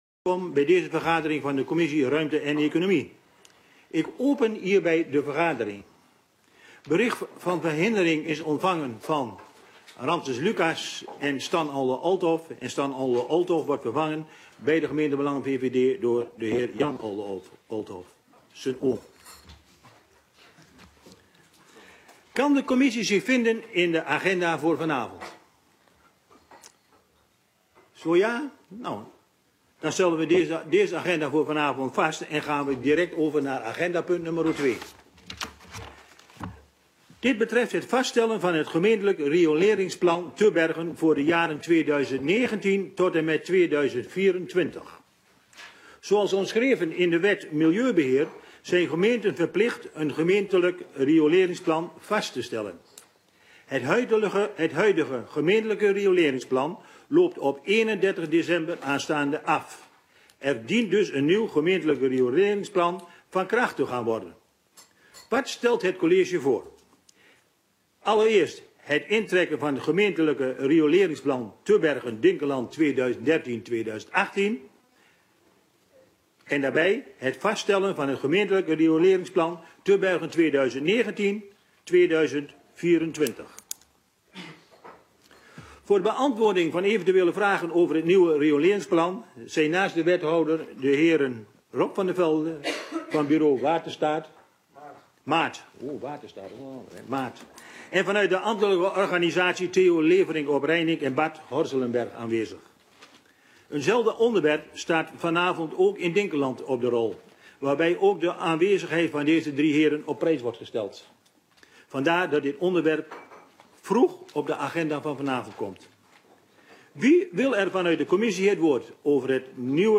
Raadzaal